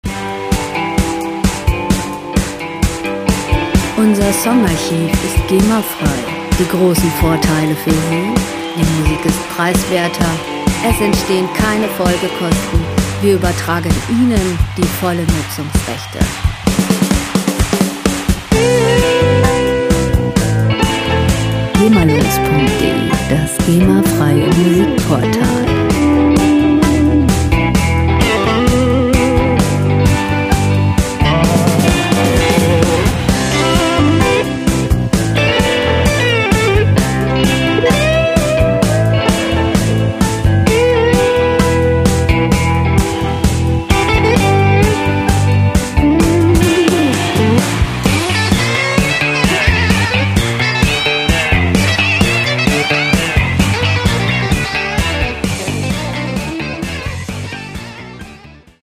Rockmusik - Legenden
Musikstil: Rock 'n' Roll
Tempo: 130 bpm
Tonart: C-Dur
Charakter: ungeschliffen, rau
Instrumentierung: E-Gitarre, Drums, E-Bass